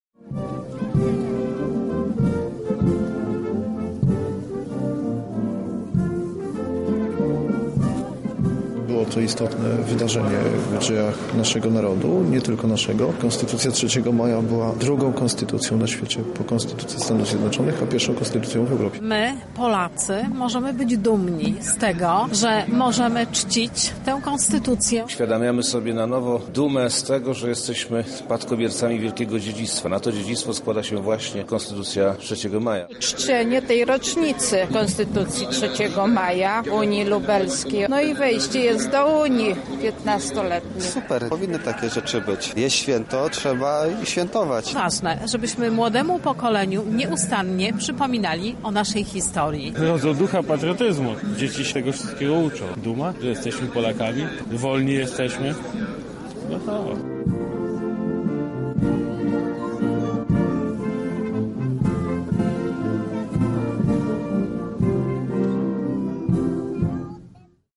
Na miejscu był nasz reporter: